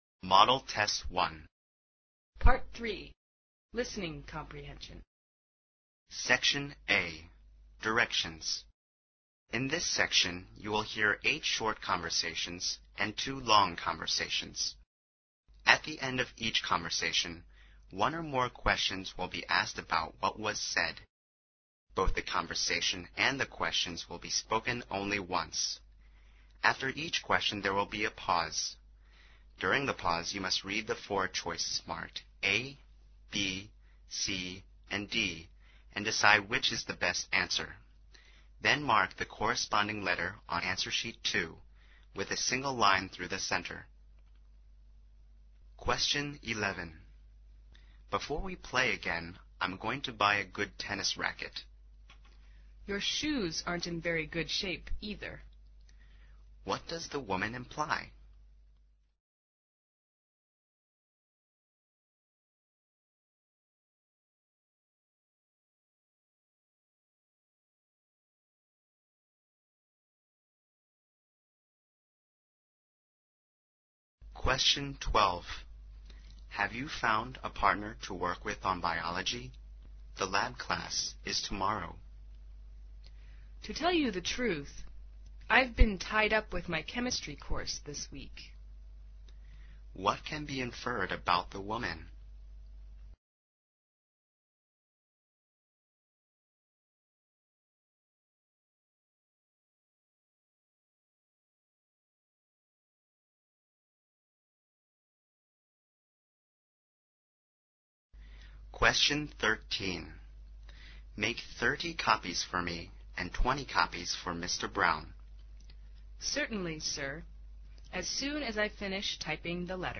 ���� Questions 19 to 21 are based on the conversation you have just heard.